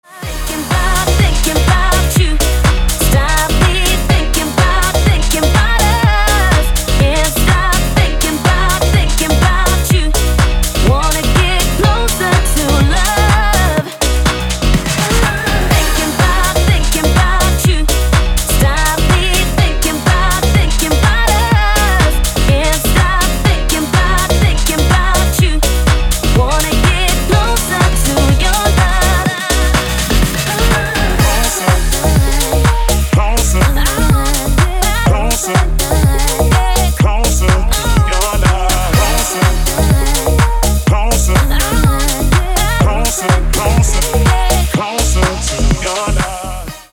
• Качество: 320, Stereo
мужской вокал
женский вокал
dance
электронная музыка
клавишные
future house
пианино